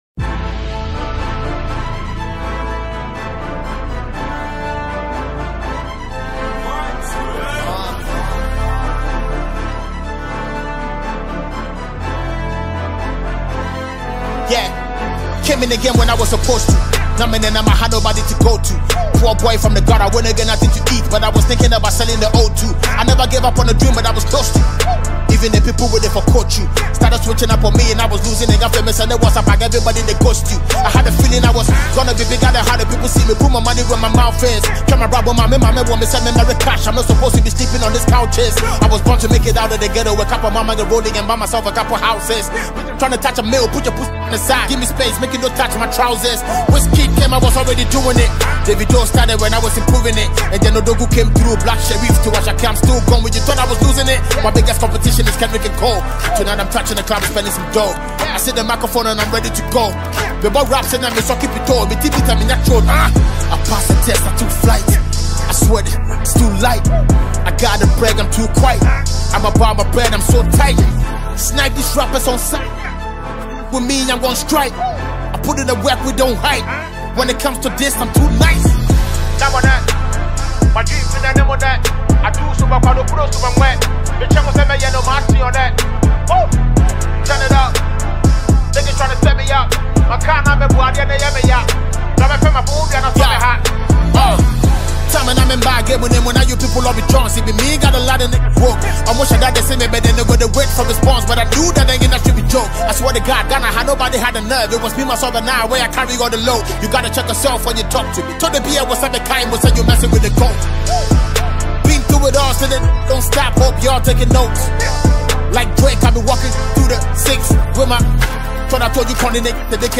the acclaimed rapper